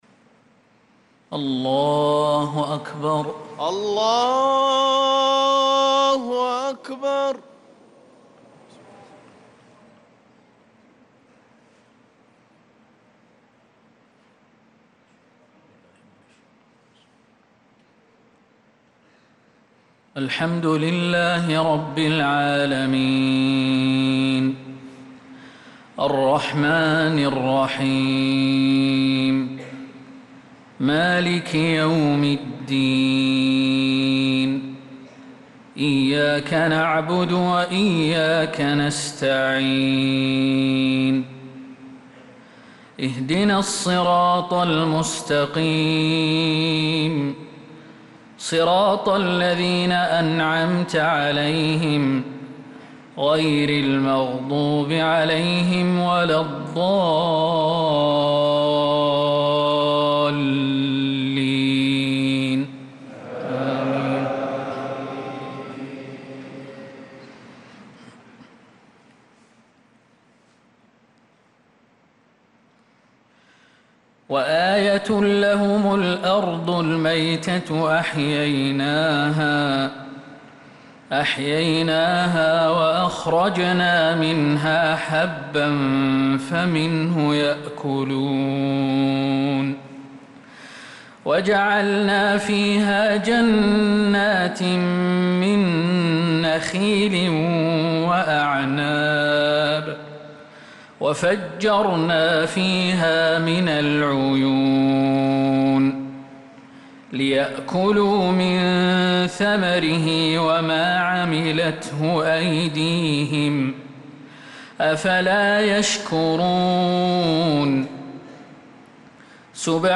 صلاة المغرب للقارئ خالد المهنا 18 ربيع الأول 1446 هـ
تِلَاوَات الْحَرَمَيْن .